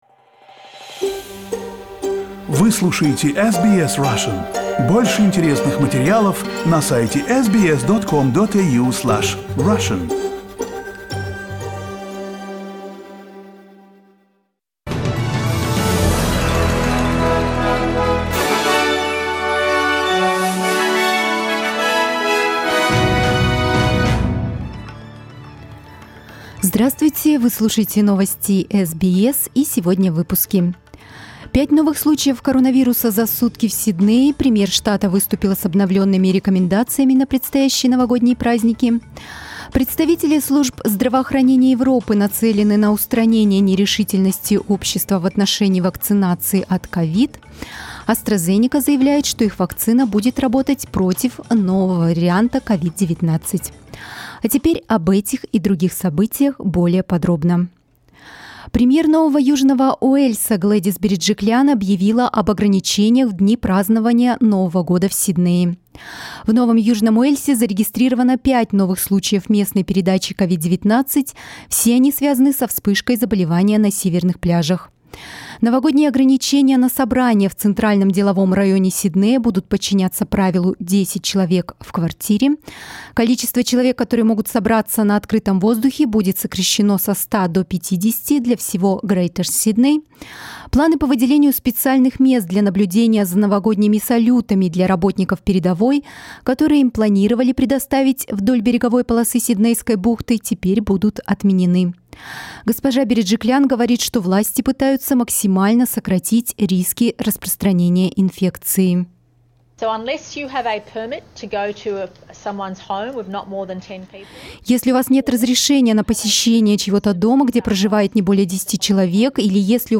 Новостной выпуск за 28 декабря